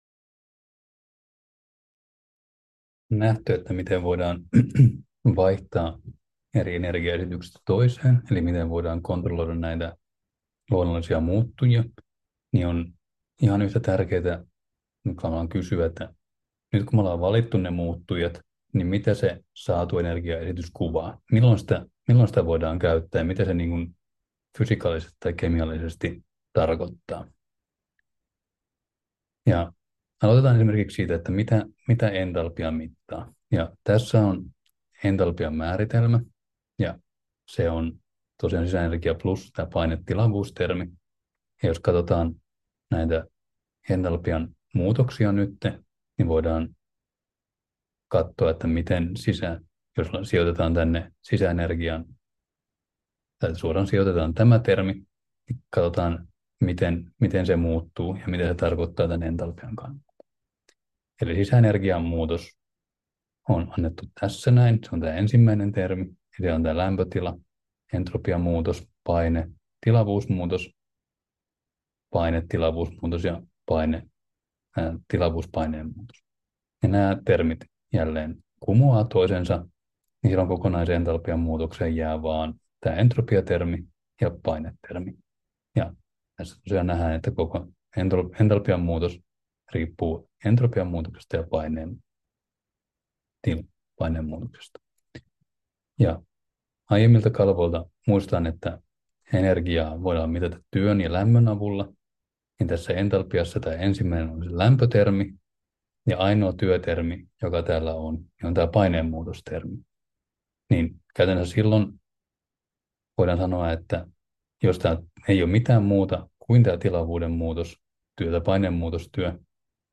Luento 6: Vapaa energia 6 — Moniviestin